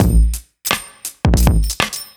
OTG_Kit6_Wonk_110b.wav